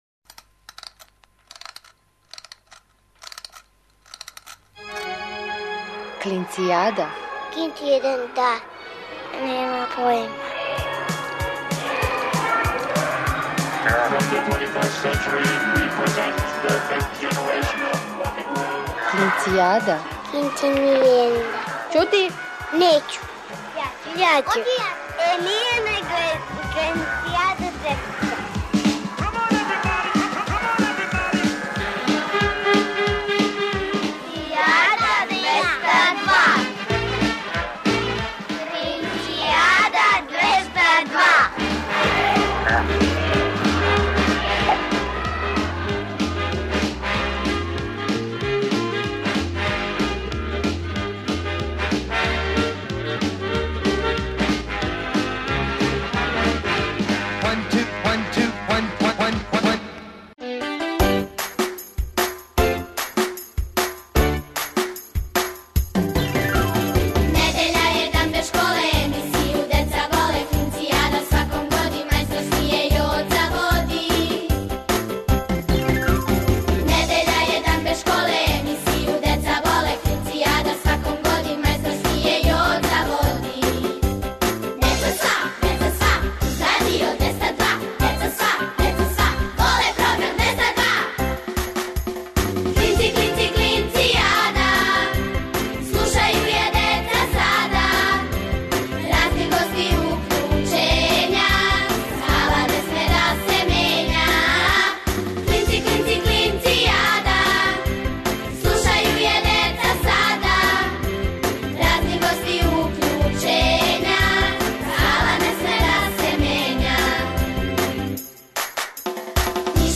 Сваке недеље уживајте у великим причама малих људи, бајкама, дечјим песмицама.